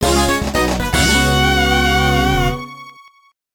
starting grid fanfare